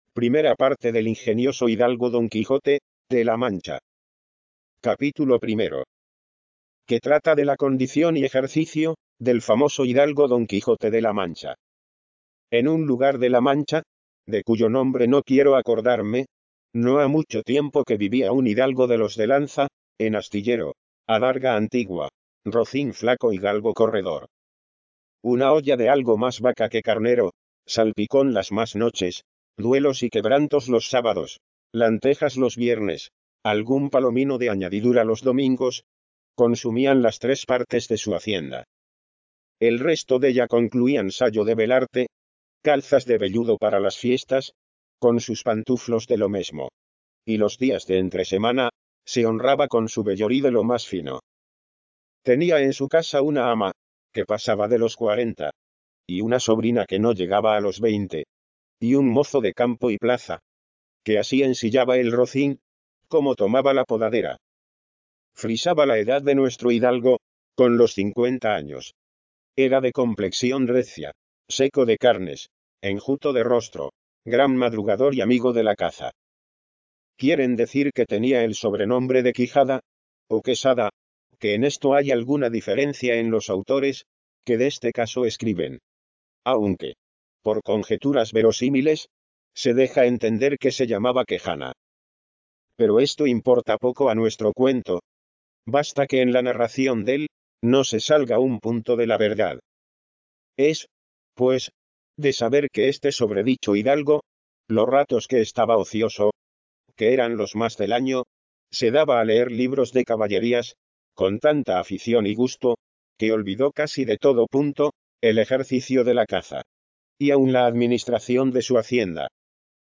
Aquí se pueden escuchar una serie de ejemplos obtenidos mediante el CTV: